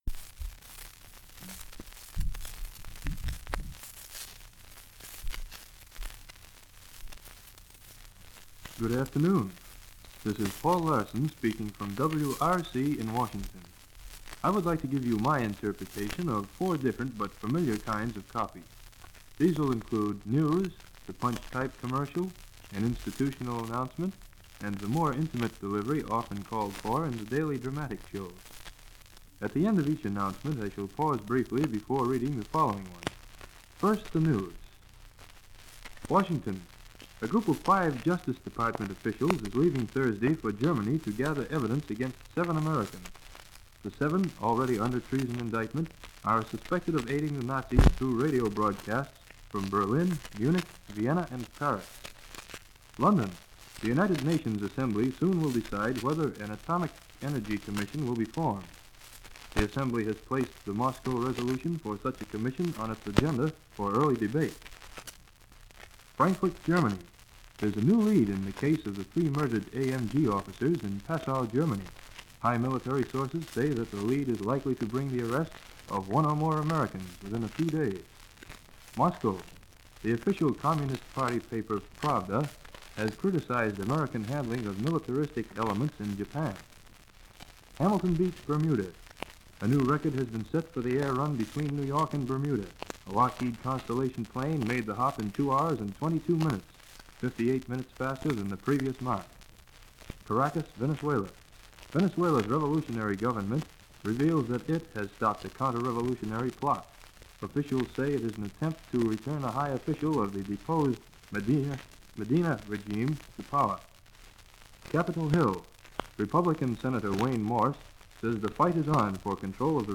This recording is from a 78 record